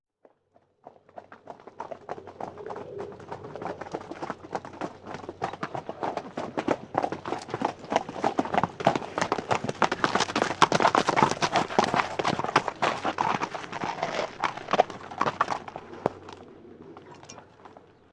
Dos caballos llegando al trote sobre tierra